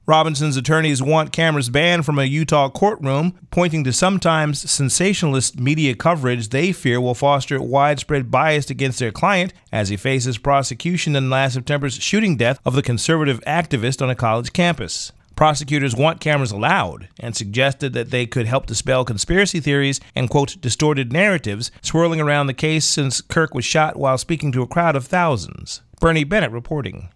reporting.